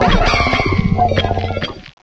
sovereignx/sound/direct_sound_samples/cries/iron_leaves.aif at 2f4dc1996ca5afdc9a8581b47a81b8aed510c3a8